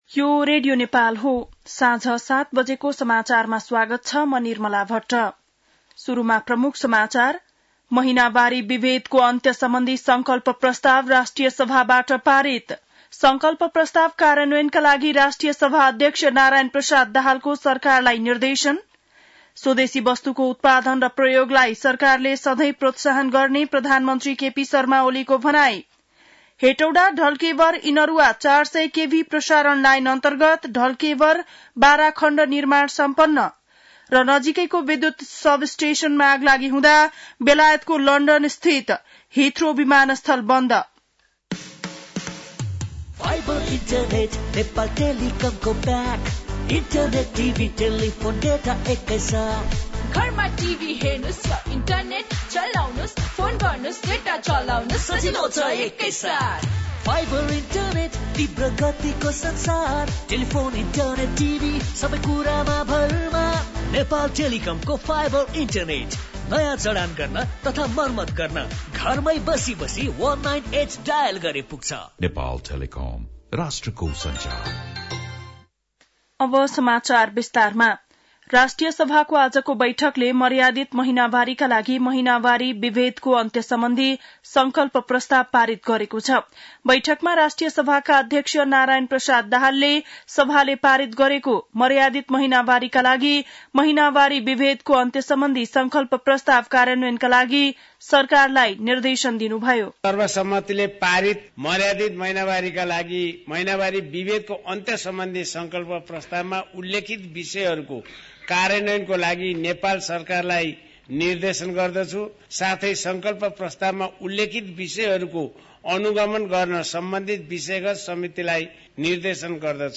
बेलुकी ७ बजेको नेपाली समाचार : ८ चैत , २०८१